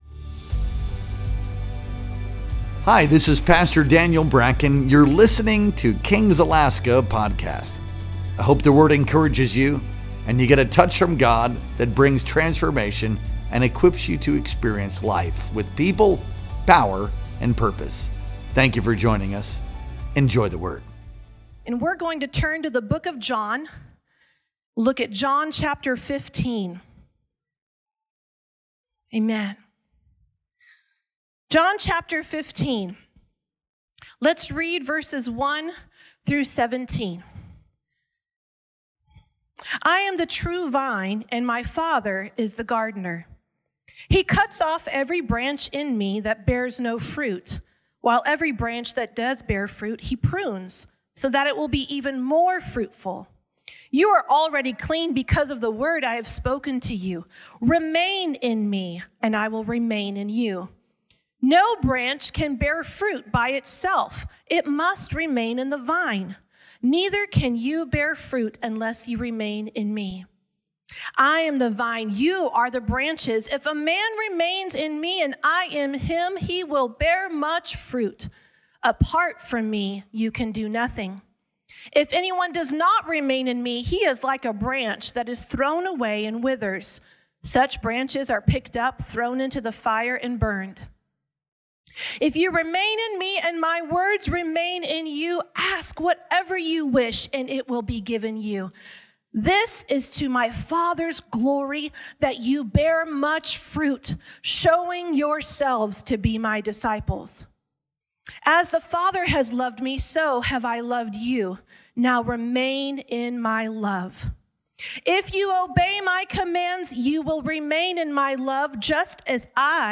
Our Sunday Night Worship Experience streamed live on August 31th, 2025.